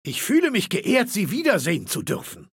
Datei:Maleold01 ms06fin greeting 000284b1.ogg
Fallout 3: Audiodialoge